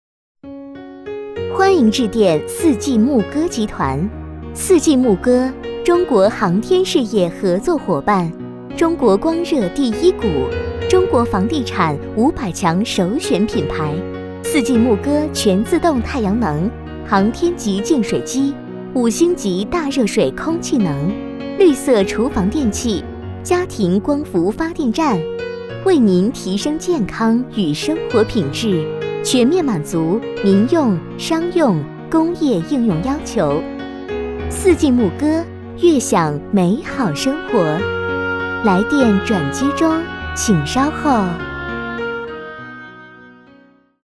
女国132_其他_彩铃_四季沐歌_温柔.mp3